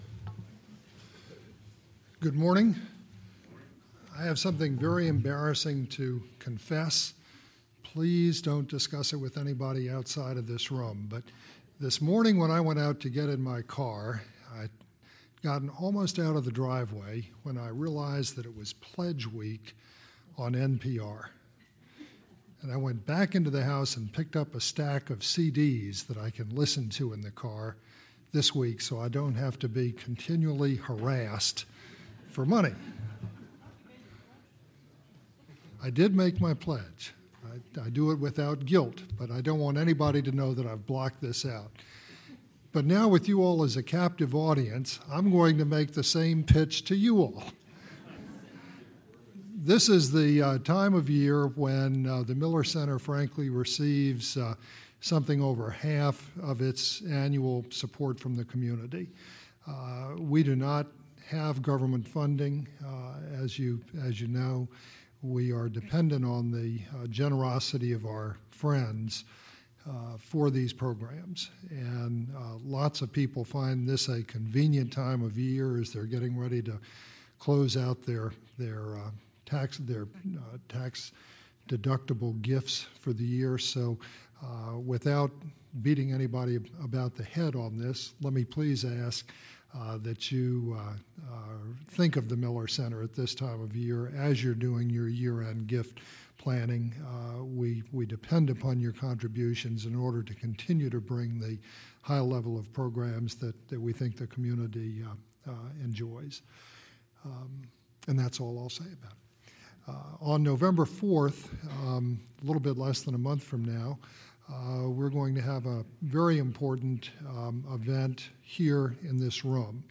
The executive director of the 9/11 Commission, Philip Zelikow, follows his September presentation of the origins of the 9/11 plot and why the U.S. was unable to stop it, with a discussion of the work of the 9/11 Commission and its recommendations.